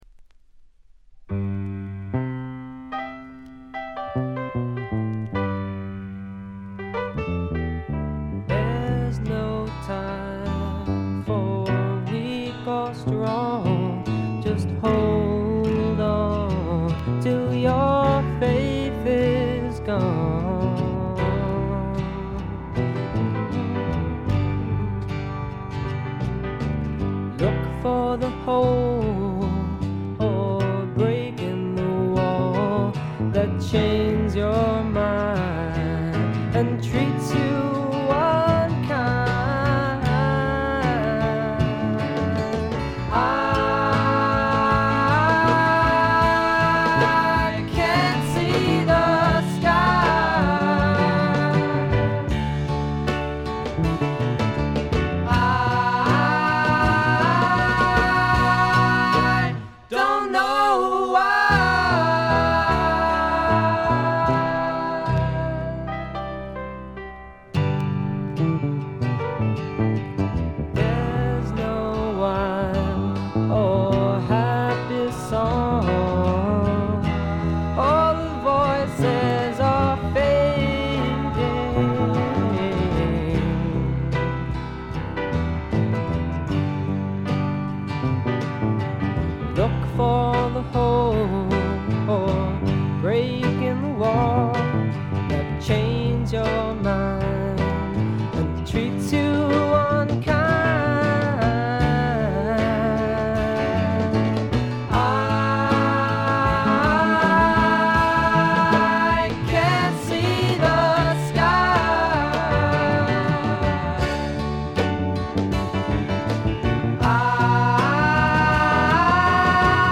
シンガーソングライター・デュオ
きらきらときらめくドリーミーフォーク的な感覚も素晴らしい。
試聴曲は現品からの取り込み音源です。